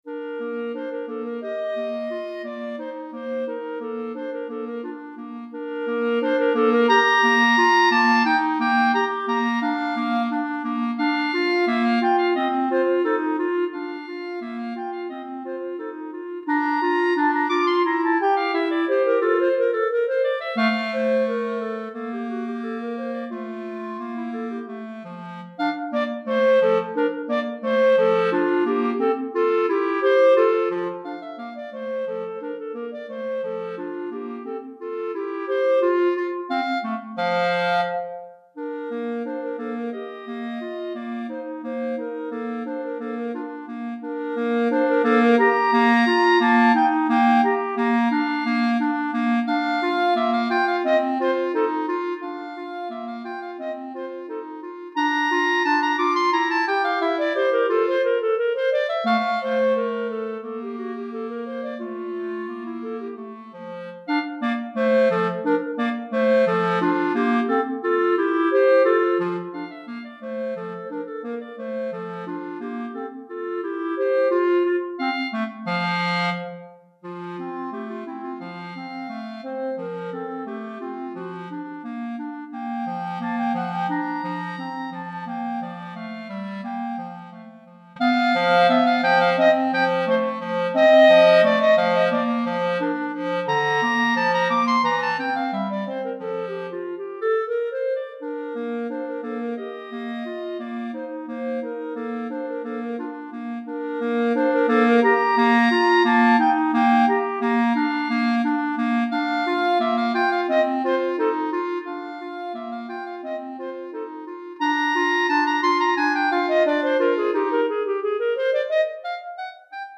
Répertoire pour Clarinette - 2 Clarinettes